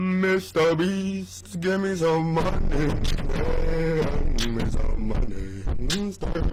mr beast give me some money Meme Sound Effect